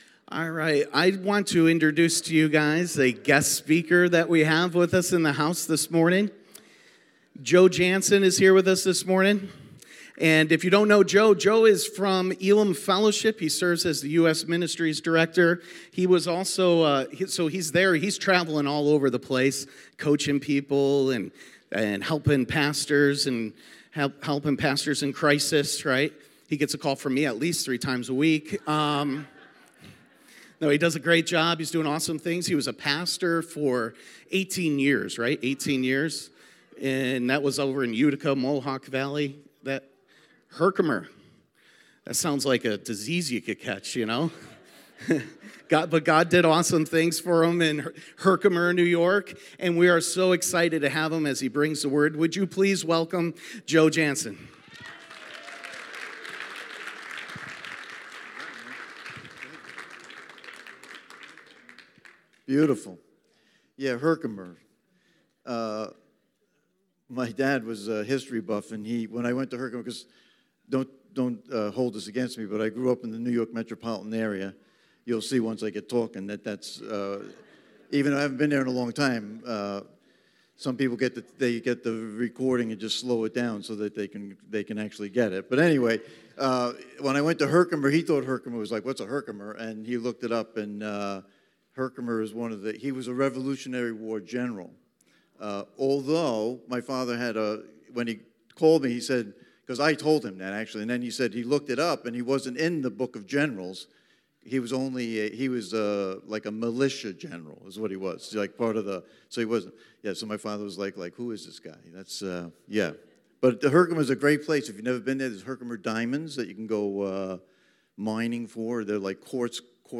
Stand Alone Message